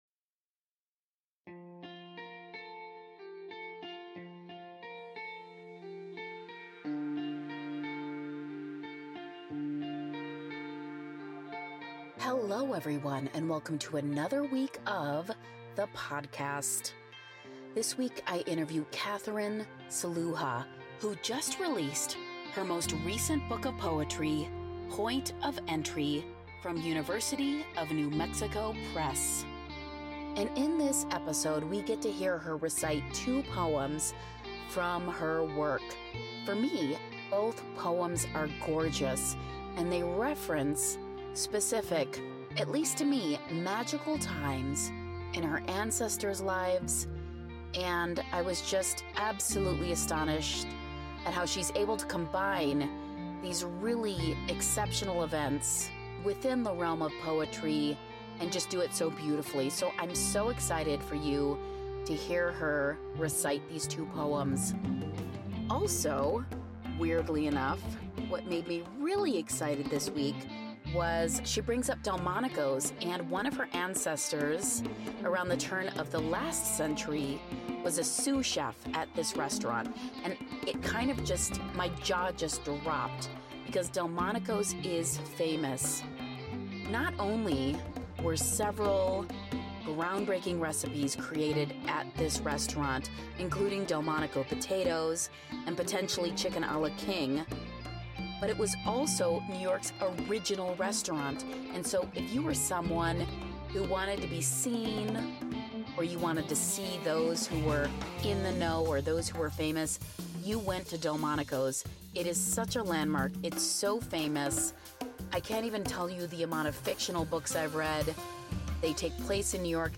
She reads two of her poems in this episode, and both are beautiful creations, and one even addresses a family miracle that happened oh so long...